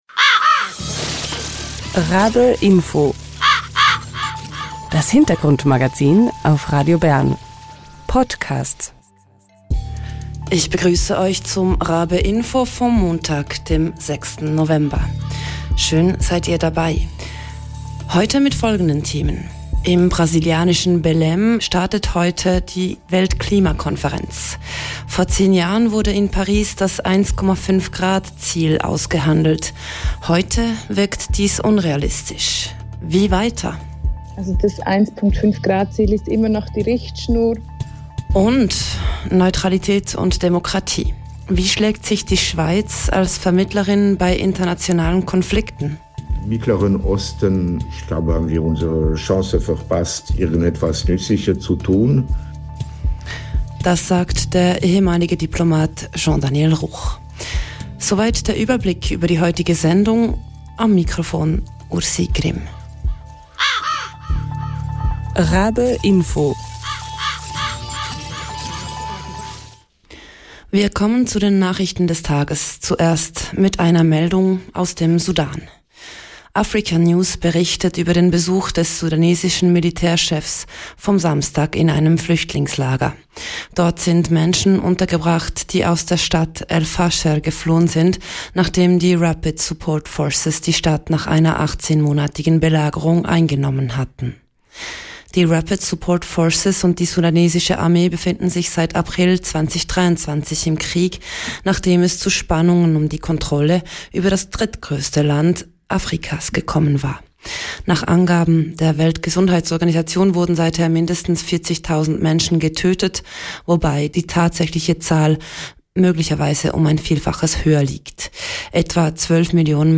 Interview
Und: Wie könnte die Schweiz als neutrale Demokratie bei internationalen Konflikten effektiv vermitteln? Darüber spricht der ehemalige Diplomat und Gründer des Genfer Zentrums für Neutralität, Jean-Daniel Ruch.